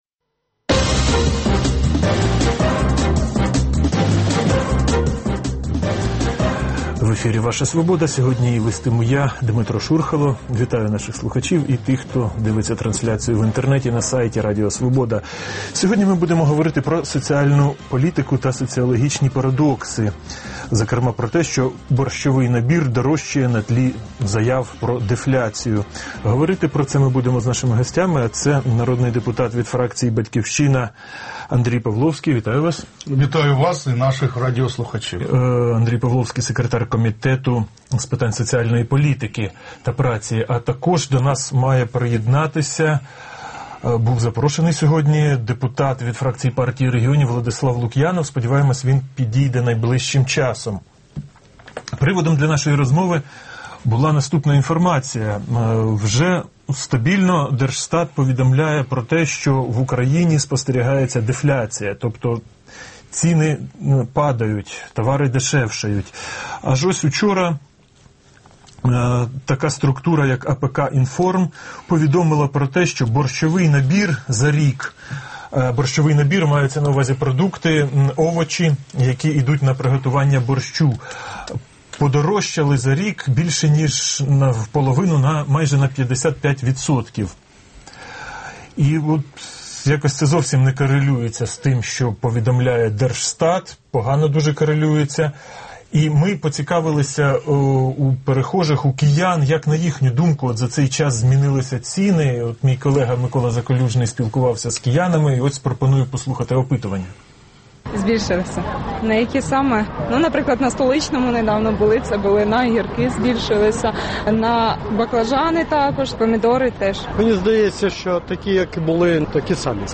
Гості: народні депутати Владислав Лук’янов (Партія регіонів) і Андрій Павловський («Батьківщина»)